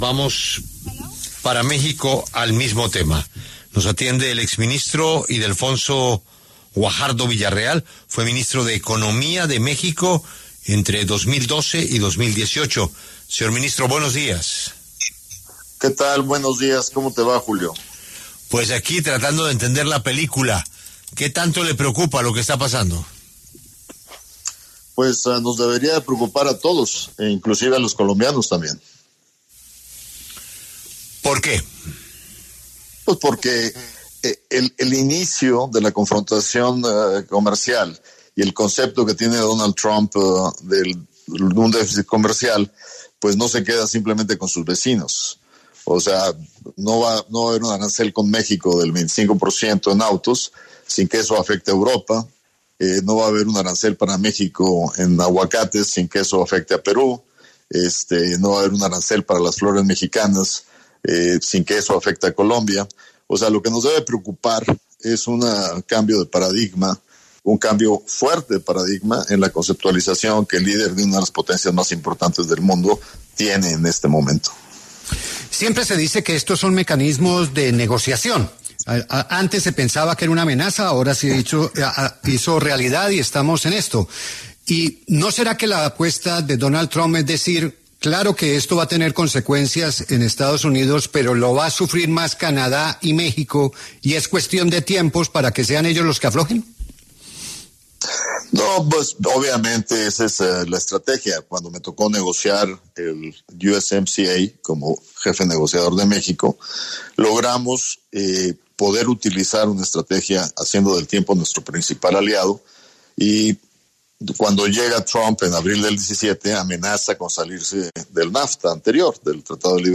Ildefonso Guajardo Villarreal, exministro de Economía de México entre 2012 y 2018, en el gobierno de Enrique Peña Nieto, conversó en los micrófonos de La W sobre la decisión de Estados Unidos de nuevos aranceles a México, Canadá y China y las repercusiones que tendrá las órdenes del presidente Donald Trump.